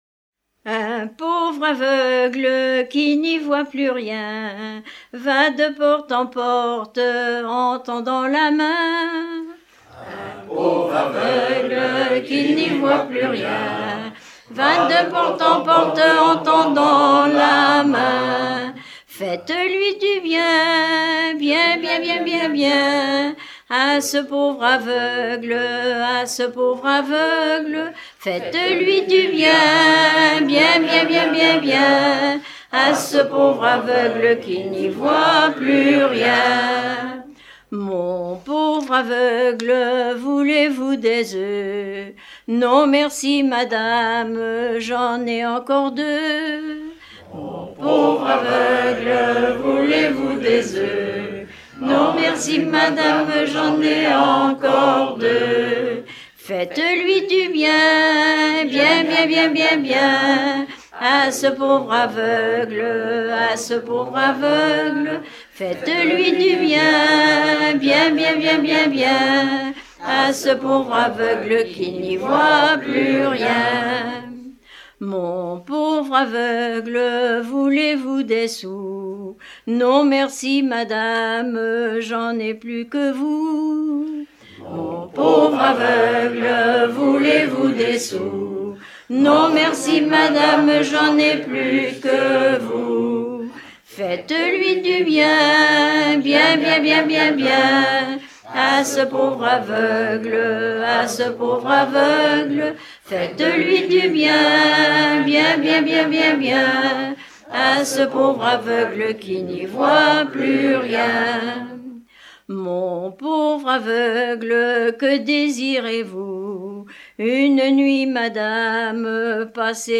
Plaine vendéenne
Pièce musicale éditée